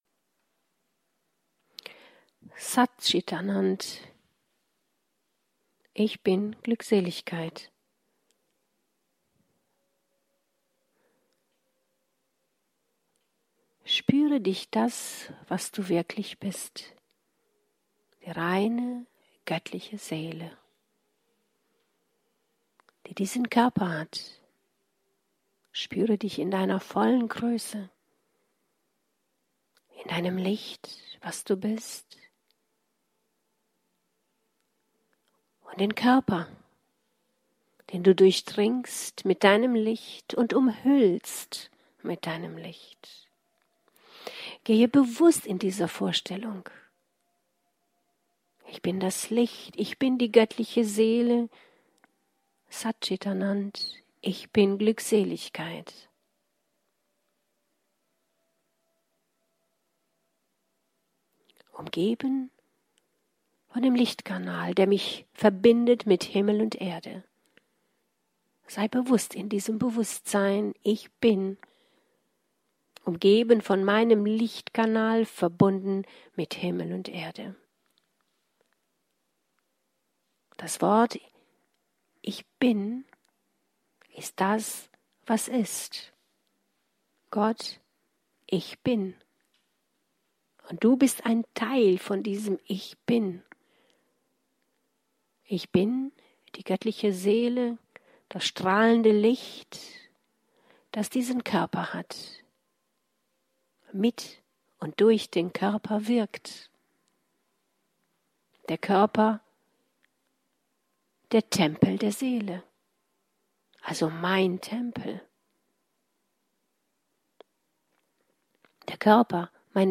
Channeling: Aktivierung der Selbstliebe - Beziehung retten, Karmische Beziehung, Liebeskummer überwinden, Schlechtes Karma auflösen